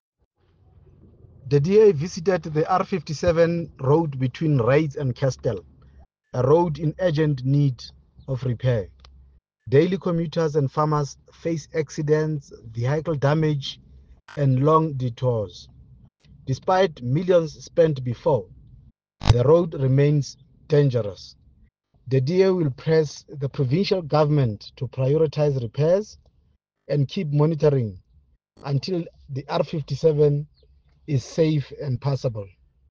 English soundbite by Cllr Diphapang Mofokeng,